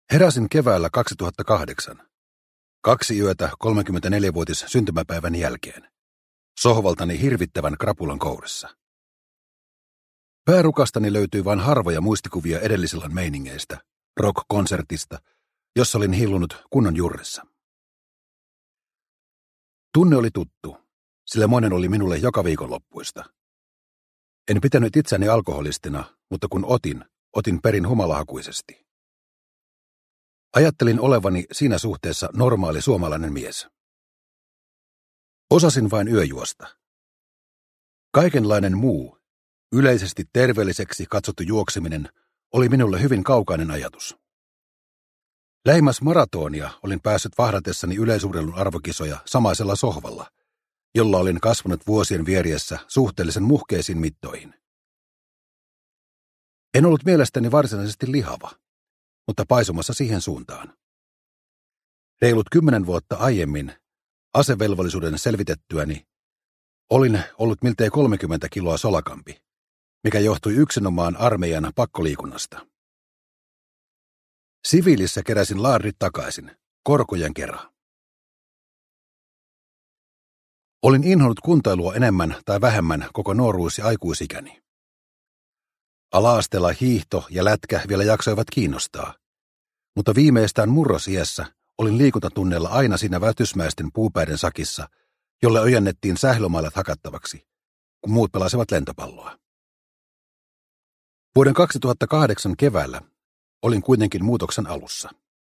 Raskas askel – Ljudbok – Laddas ner